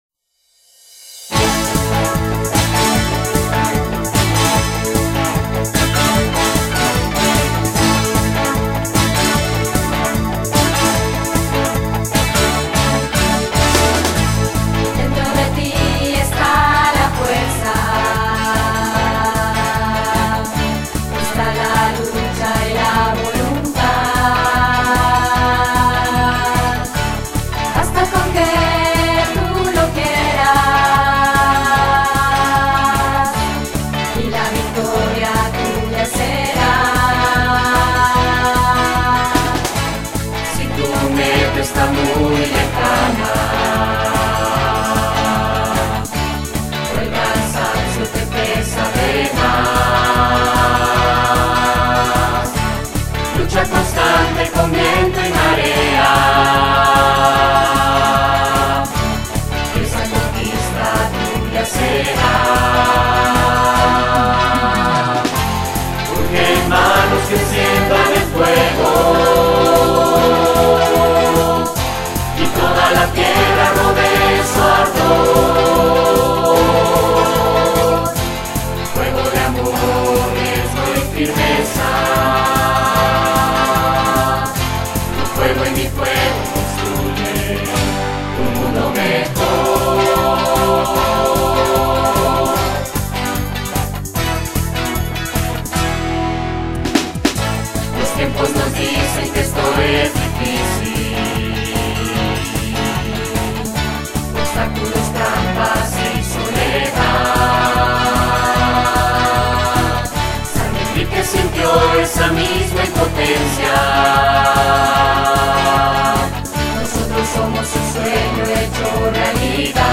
HIMNO